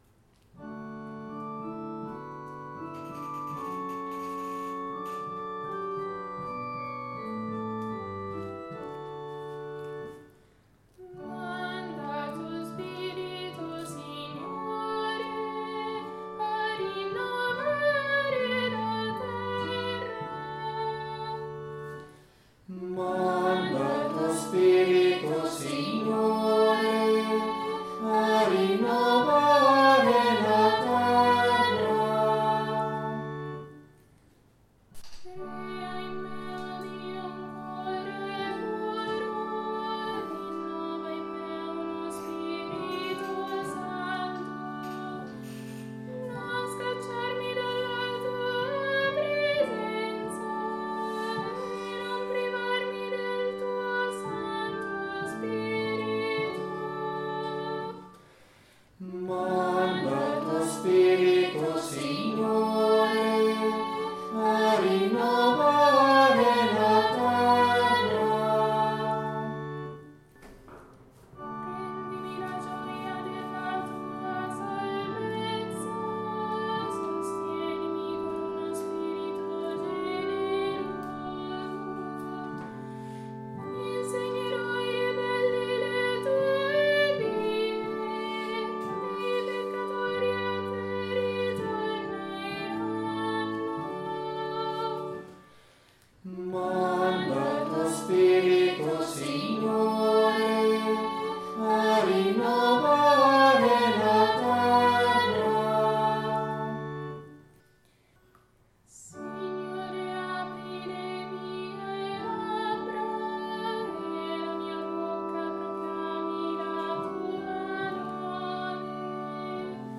Audio Esecuzione coro Vexilla Regis – Milano